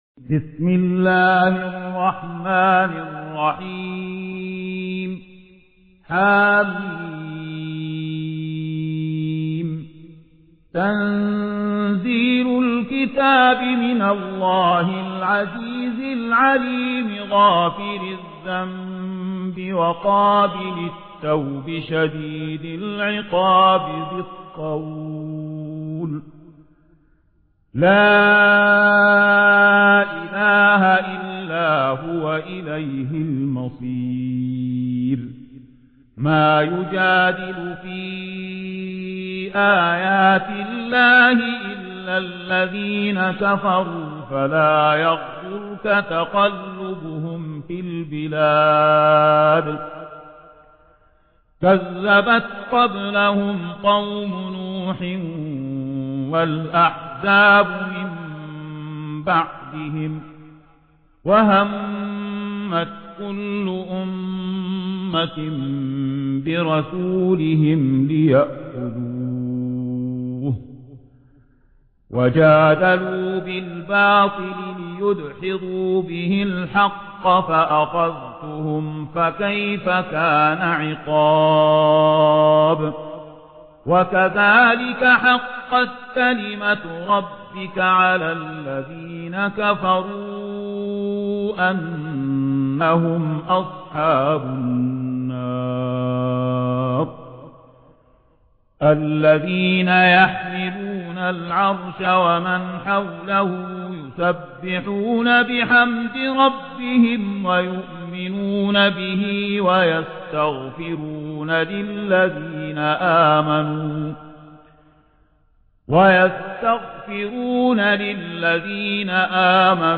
قرآن